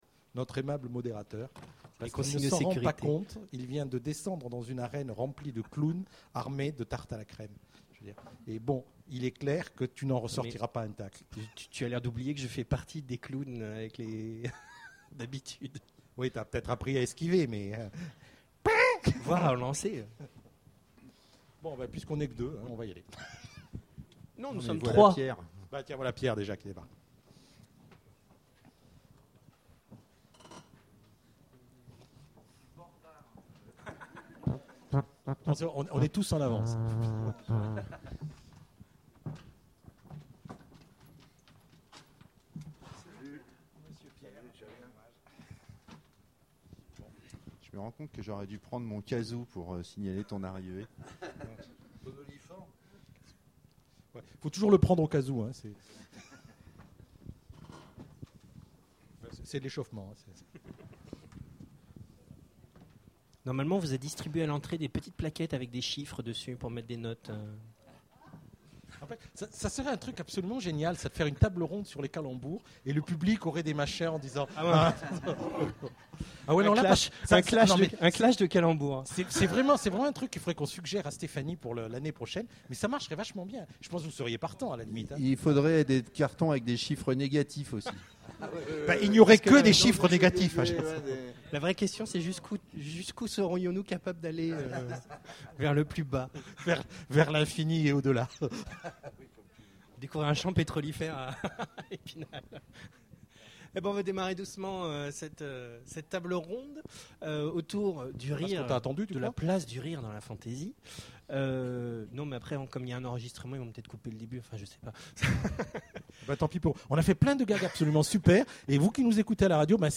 Imaginales 2014 : Conférence Et l'humour, dans tout ça ?
(attention, malheureusement la conférence est un peu tronquée au début...).